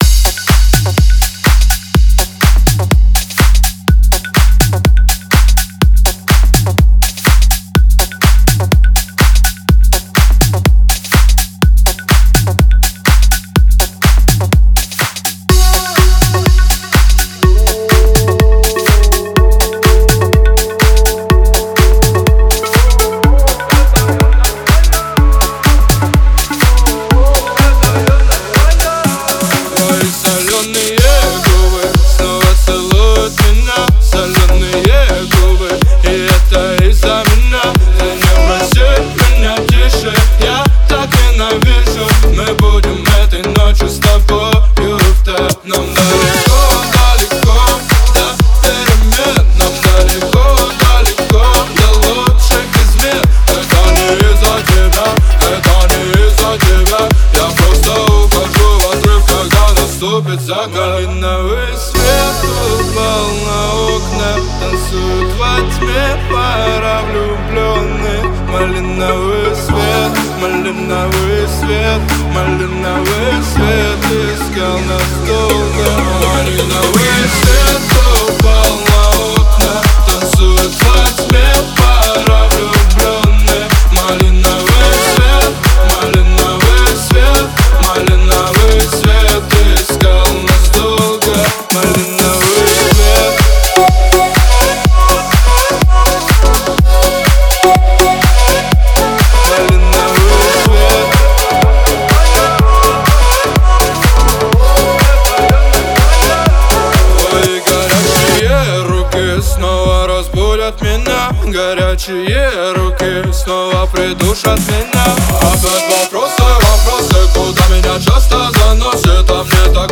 это современная поп-композиция